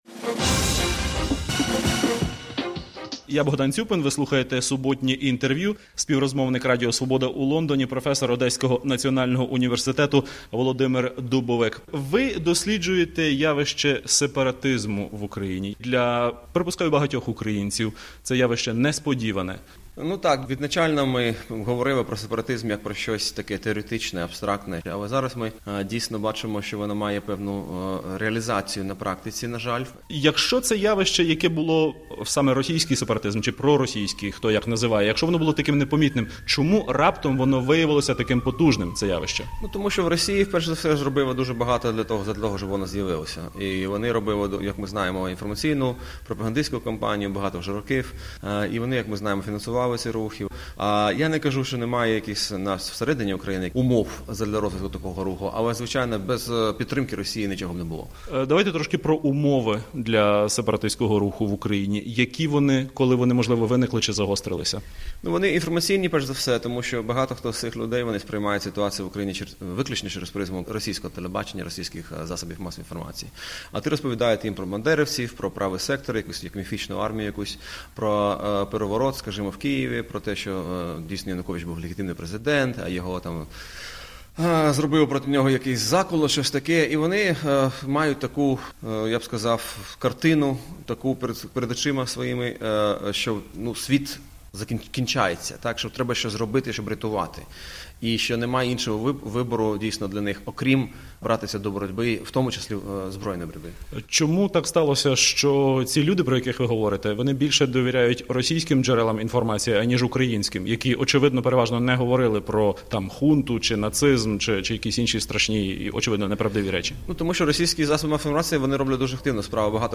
Суботнє інтерв'ю